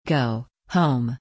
GO /ɡoʊ/, HOME /hoʊm/
発音のこつ 強めの「オ」に控えめな「ゥ」を繋げる、「オゥ」というようなイメージです。
go.mp3